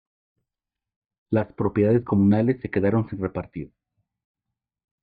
re‧par‧tir
/repaɾˈtiɾ/